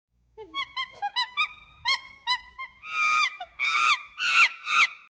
Chipanzé Efeito Sonoro: Soundboard Botão
Chipanzé Botão de Som